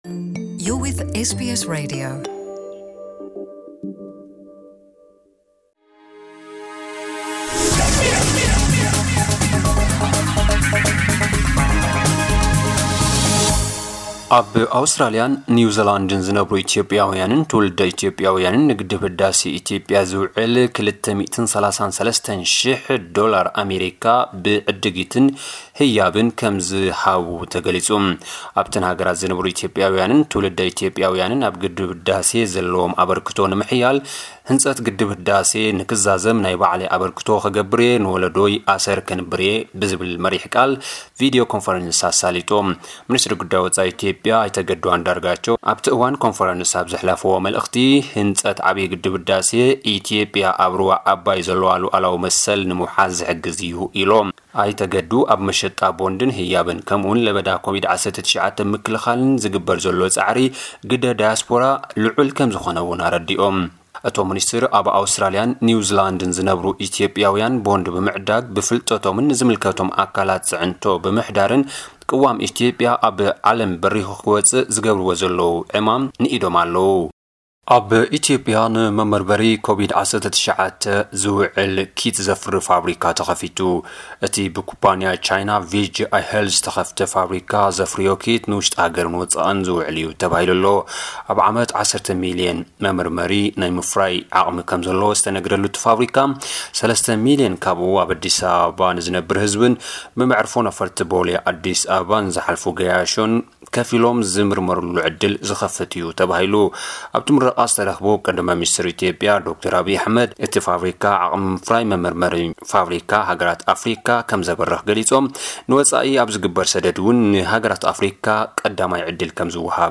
ሓጸርቲ ጸብጻባት ዜና (14/09/2020) *** ዕስለ ኣንበጣ ኣብ ጋሽባርካ ኣብ ትሕቲ ቁጽጽር ኣትዩ *** ዳያስፖራ ኢትዮጵያውያን ኣብ ኣውስትራልያን ኒውዚላንድን ንግድብ ህዳሰ ዝውዕል ሓገዛት ኣበርኪቶም